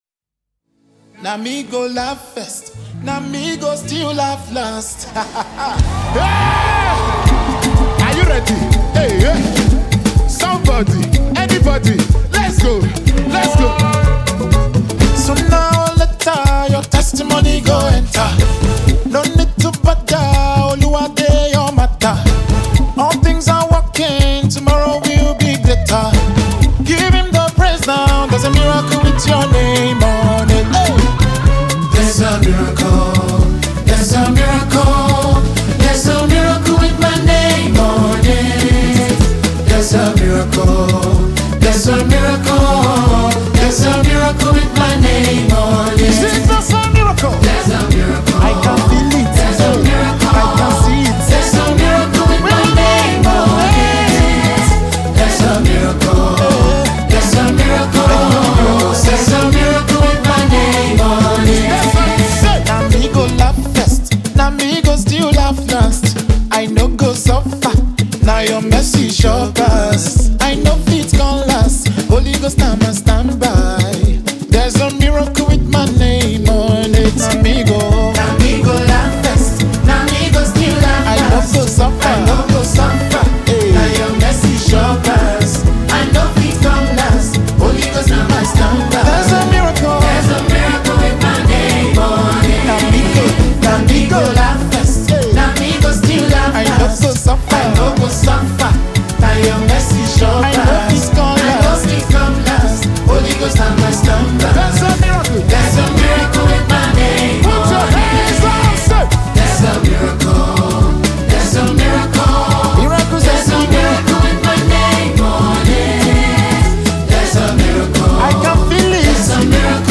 gospel
a powerful new worship sound
Through heartfelt lyrics and a moving melody